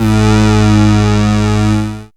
37aa01syn-g#.wav